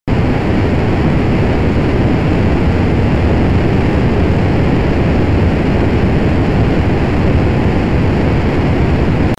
دانلود آهنگ موشک 9 از افکت صوتی حمل و نقل
جلوه های صوتی
دانلود صدای موشک 9 از ساعد نیوز با لینک مستقیم و کیفیت بالا